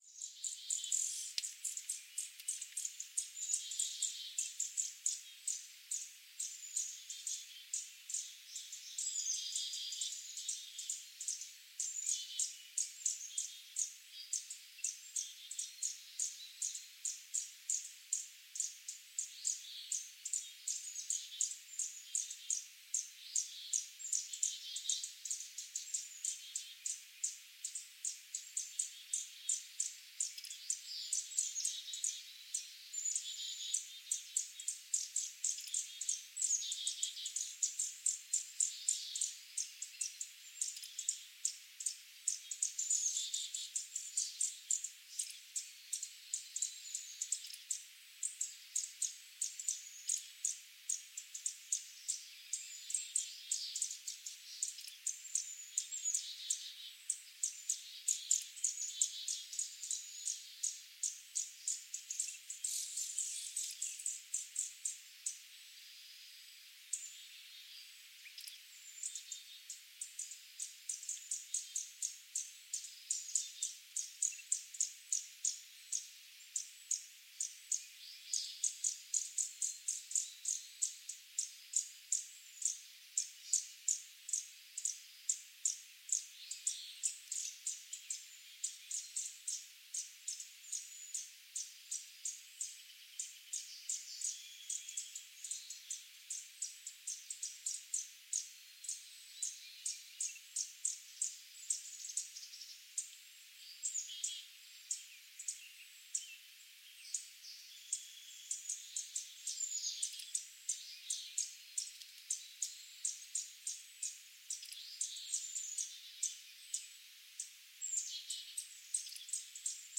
The fledgling bird in the following picture along with a sibling (not shown) and an adult (also not shown) were seen flitting around in some cottonwoods somewhere in Canada during the summer of 2013.
Clue #1: Here is a recording of the begging calls (the continuous clicking sounds) made by the 2 young birds as they chased an adult bird through the cottonwoods.